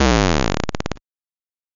8 Bit Drop.wav